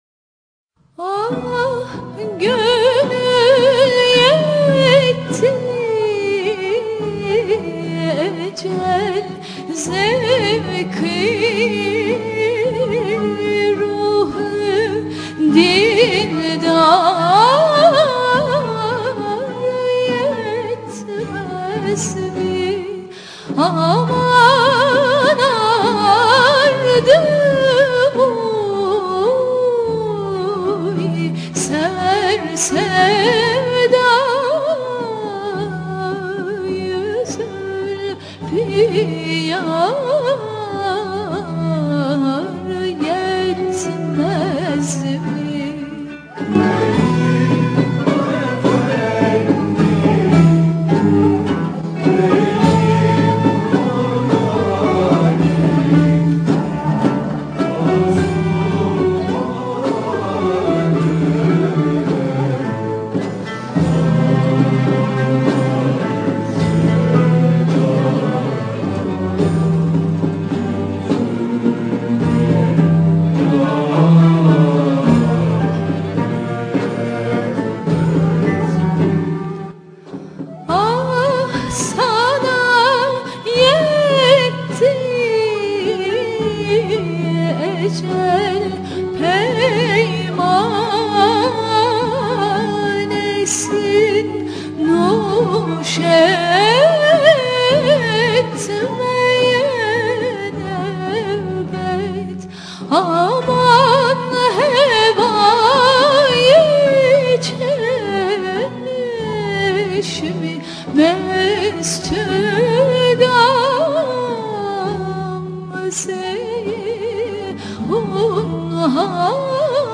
Usûl: Aksak Semai